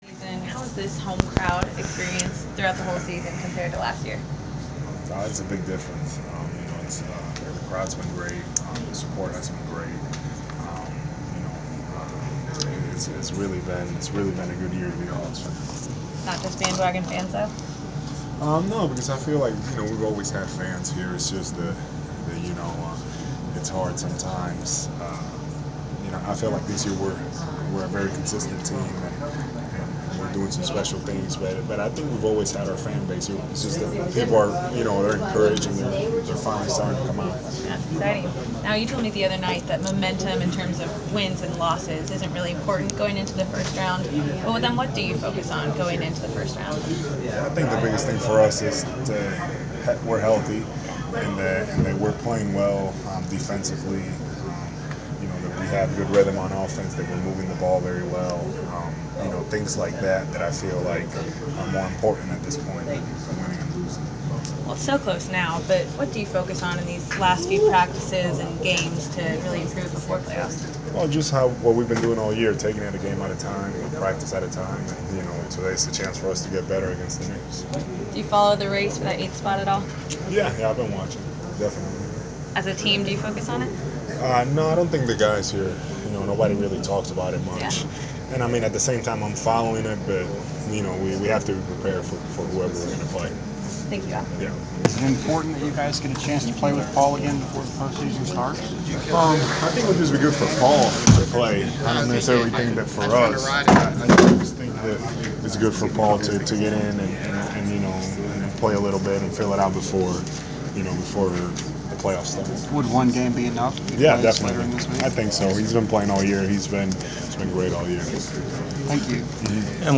Inside the Inquirer: Pregame presser with Atlanta Hawks’ Al Horford (4/13/15)
We caught up with Atlanta Hawks’ center Al Horford before his team’s home contest against the New York Knicks on April 13. Topics included the team’s preparation for the playoffs, recovery of Paul Millsap, the coaching of Mike Budenholzer, the Hawks’ potential MVP candidate and his chances to be named to an All-NBA team.